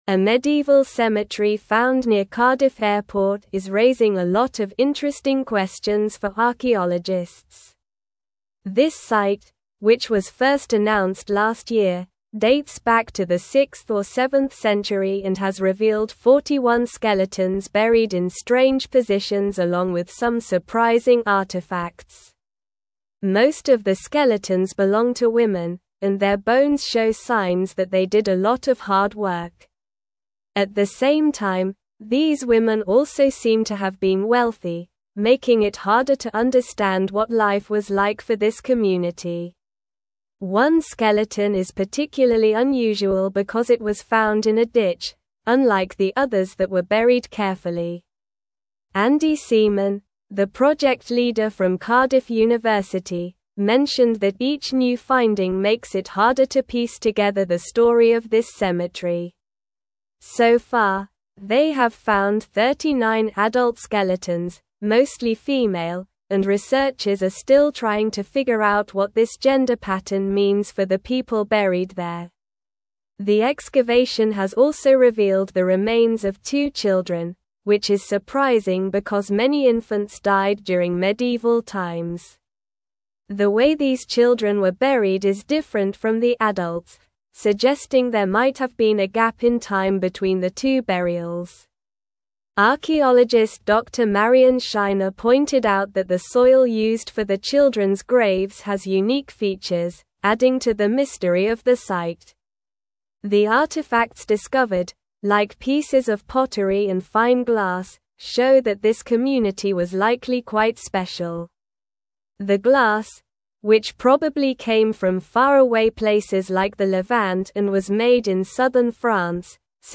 Slow
English-Newsroom-Upper-Intermediate-SLOW-Reading-Medieval-Cemetery-Near-Cardiff-Airport-Unveils-Mysteries.mp3